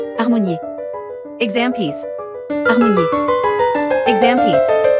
• 人声数拍
• 大师演奏范例
我们是钢琴练习教材专家